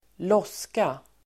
Ladda ner uttalet
loska substantiv (vardagligt), gob [informal]Uttal: [²l'ås:ka] Böjningar: loskan, loskorSynonymer: spottaDefinition: spottklump